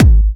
VEC3 Bassdrums Trance 36.wav